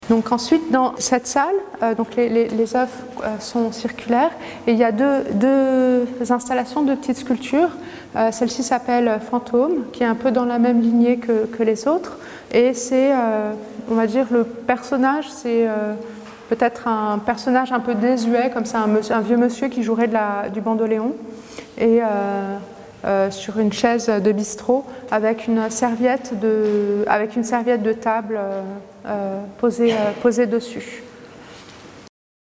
Le macLYON a proposé à Latifa Echakhch de parler de chacune des œuvres présentes dans l'exposition Laps, et a intégré ces séquences sonores à ses cartels.